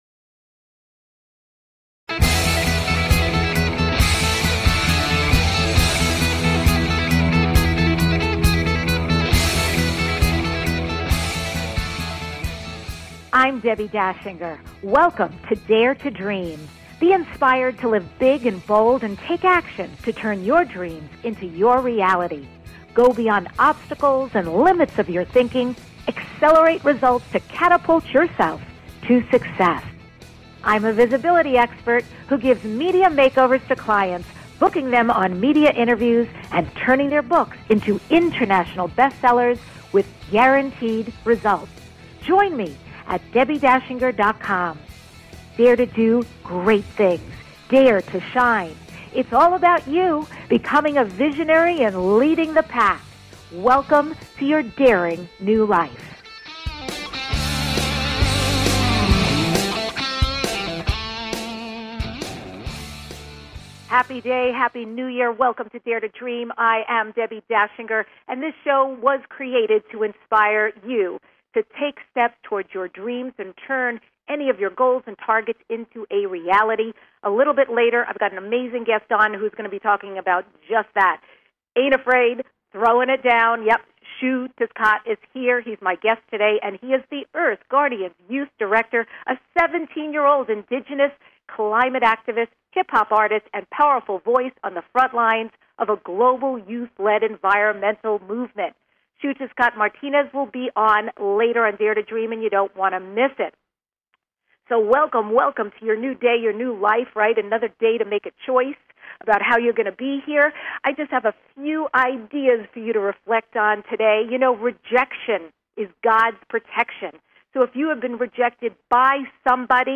Guest, Xiuhtezcatl Martinez